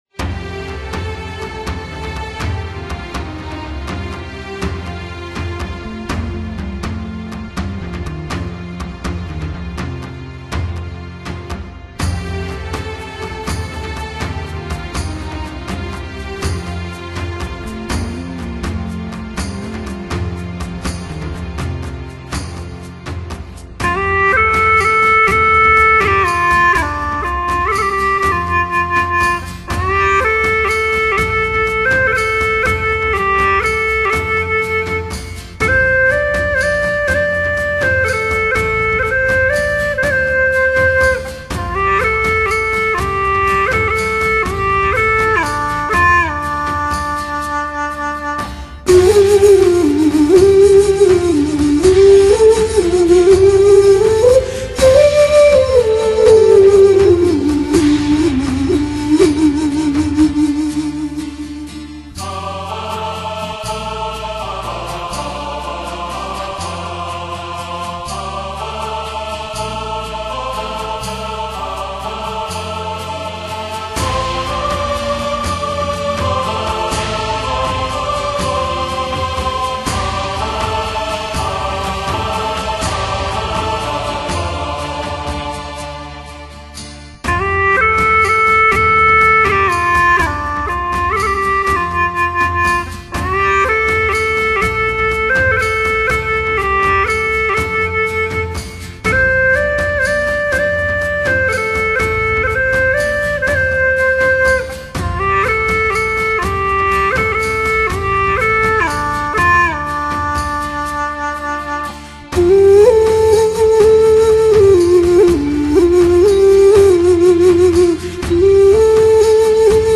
(葫芦丝&埙)